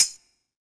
BTAMBOURIN2O.wav